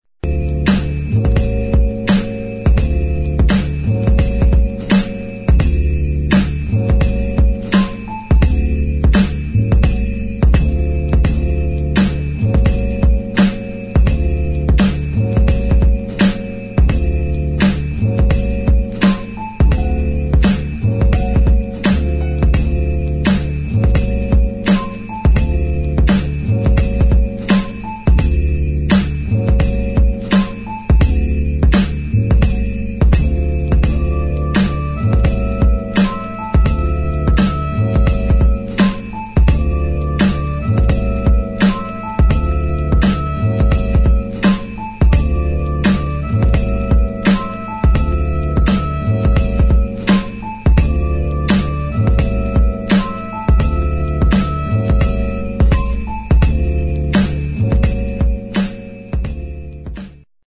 [ HIP HOP ]
Instrumental